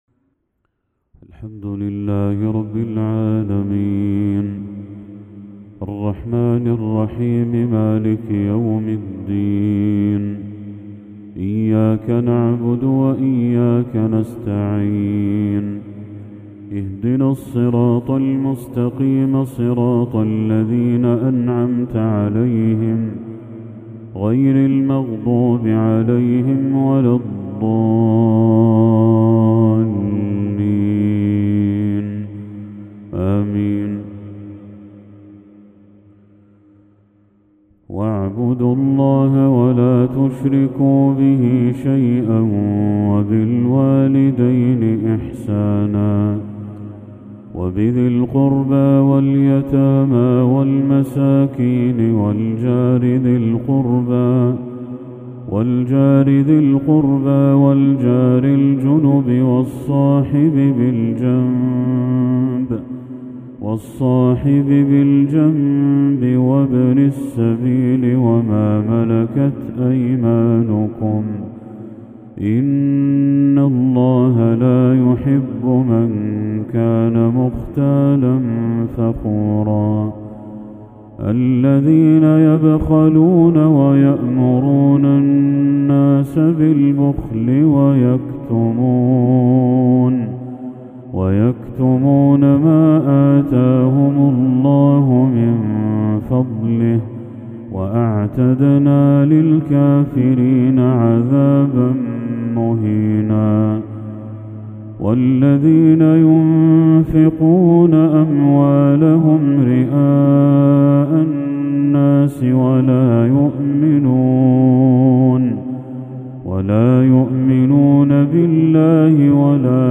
تلاوة خاشعة من سورة النساء للشيخ بدر التركي | عشاء 3 محرم 1446هـ > 1446هـ > تلاوات الشيخ بدر التركي > المزيد - تلاوات الحرمين